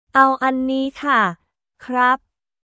【屋台で使えるタイ語フレーズ①】
アオ・アン・ニー・カ／クラップ